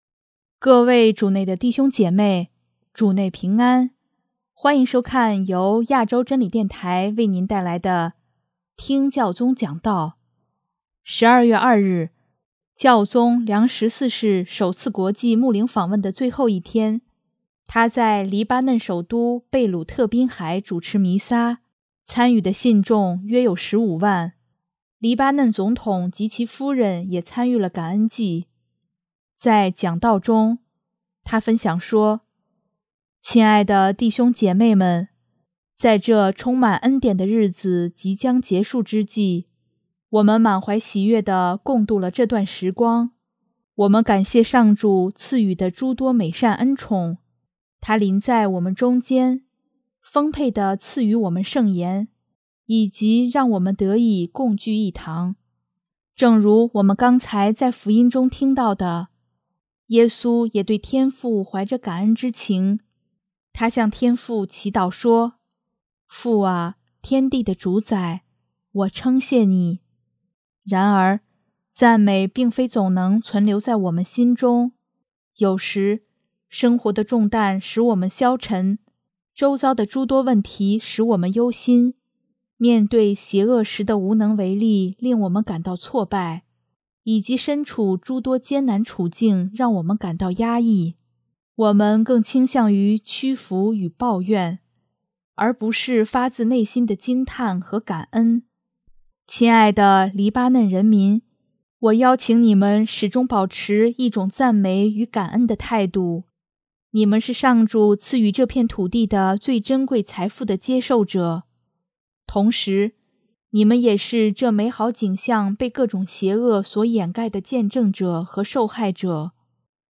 12月2日，教宗良十四世首次国际牧灵访问的最后一天，他在黎巴嫩首都贝鲁特滨海主持弥撒，参与的信众约有十五万。